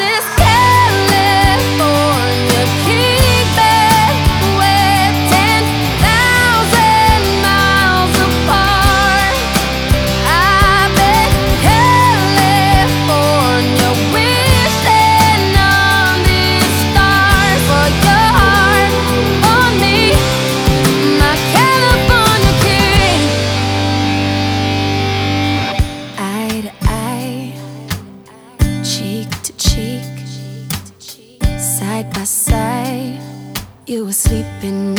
Жанр: Танцевальные / Поп / R&b / Рок / Соул